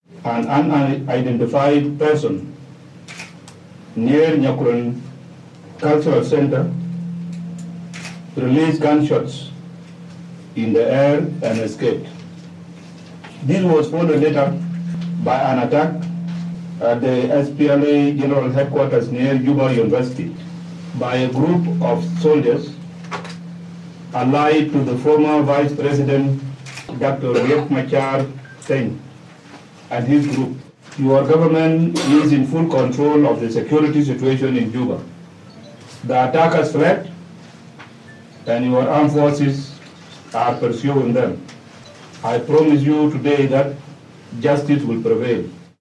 Statement by Salva Kiir